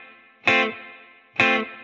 DD_TeleChop_130-Fmaj.wav